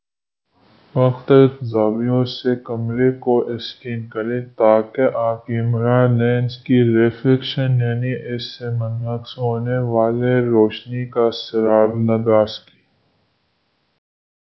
deepfake_detection_dataset_urdu / Spoofed_TTS /Speaker_17 /116.wav